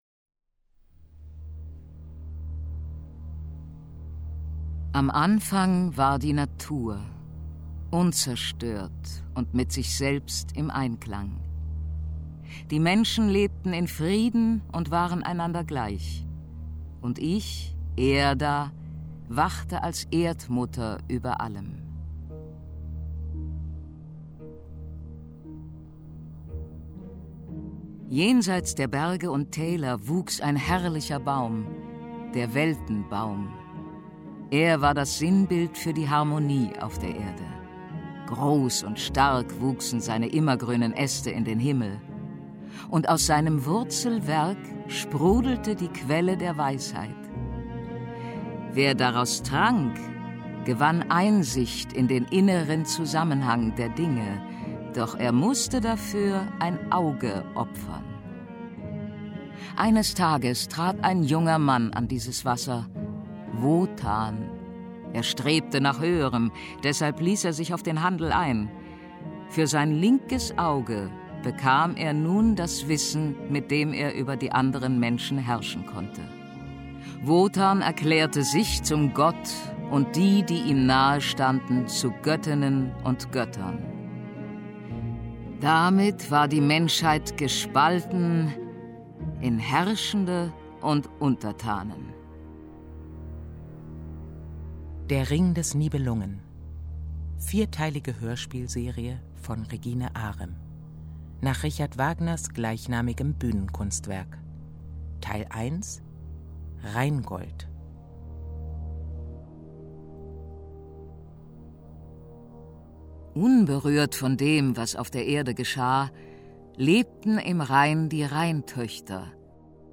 Das Rheingold Hörspiel
Regina Lemnitz , Bernhard Schütz , Martina Gedeck (Sprecher)